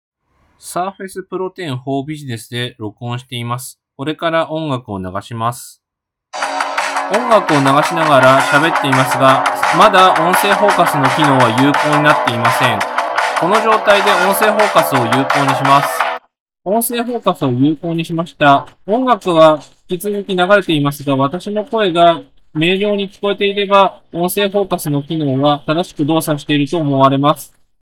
ただ、デモする際に雑音になる音源で良さそうなものを保有していなかったので、Microsoft Clipchamp 内にある、[Never Settle] という BGM を利用させていただきました。
音楽が含まれるため、ボリュームに注意してください。
SP10B_Windows_Studio_Effects_Voice-Focus_Demo.m4a